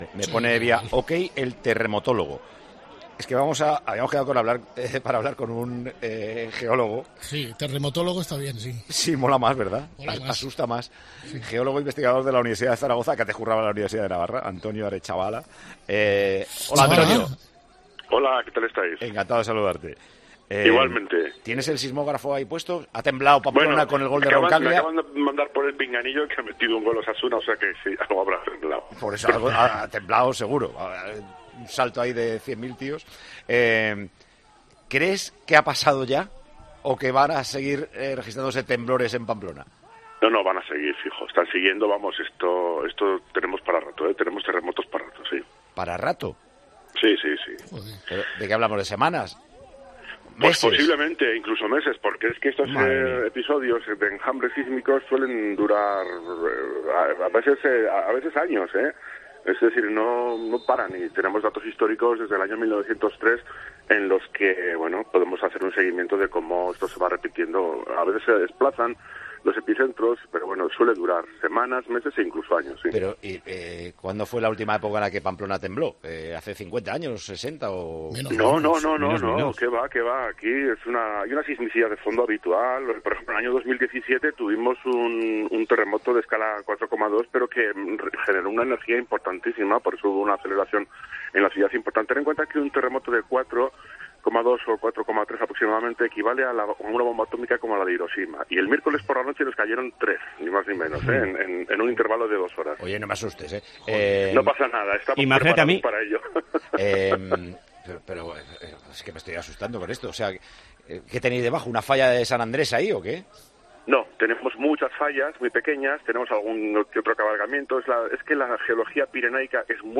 partició en Tiempo de Juego con Paco González durante el partido de Osasuna para explicar cómo es la situación de Navarra en lo referente a los terremotos